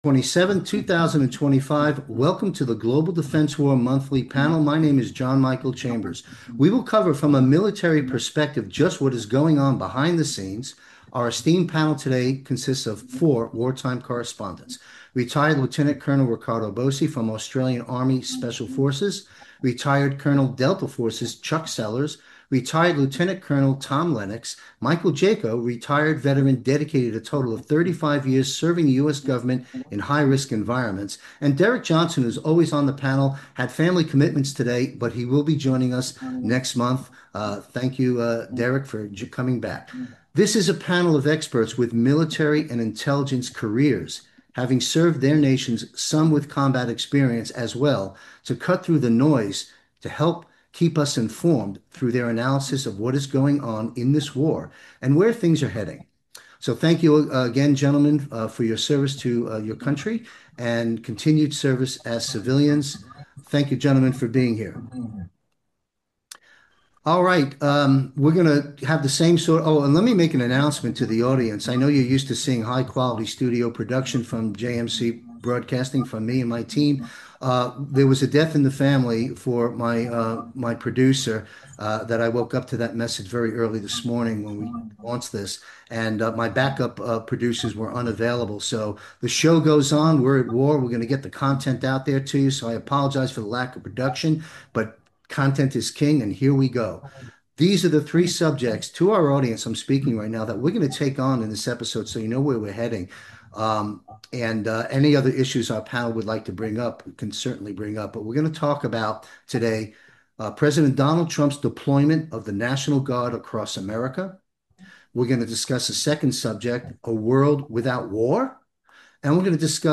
Military Roundtable experts on the coming crises in US cities and much more.
The panelists, including retired military personnel, provided their insights based on their experiences and knowledge.